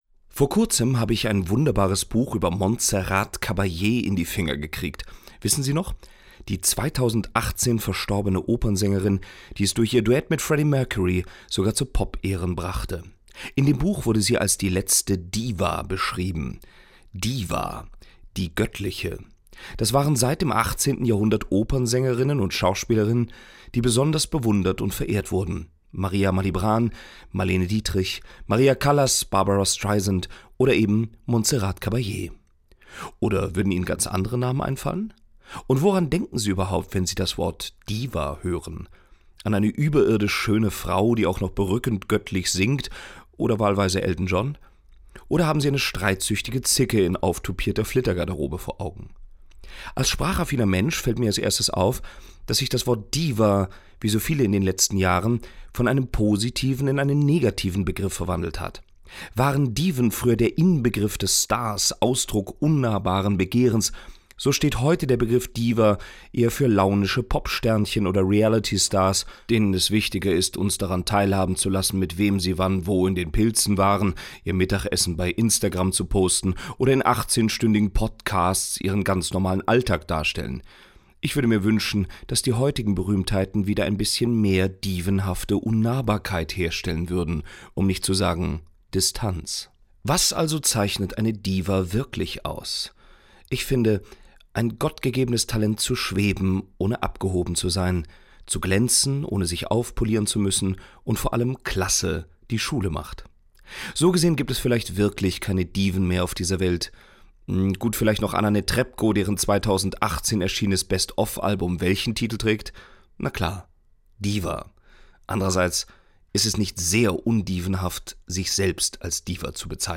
Glosse